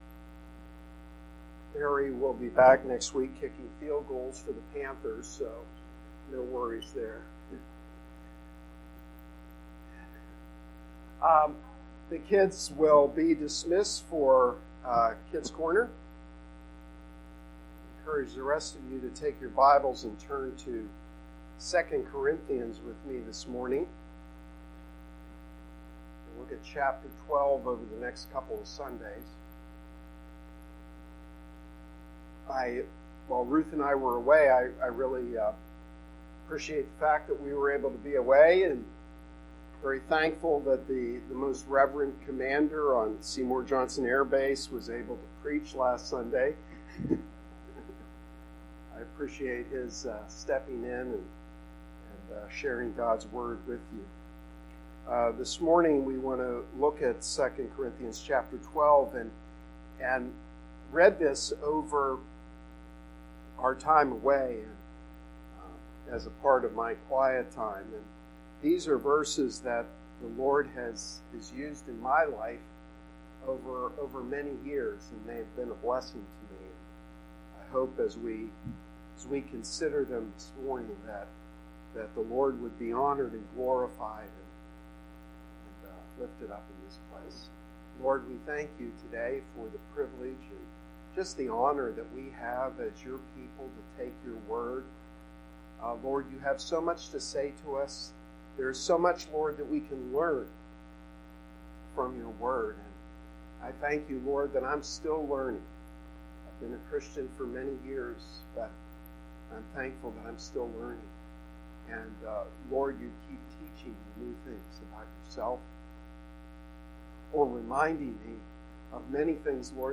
Sermon-9-22-19.mp3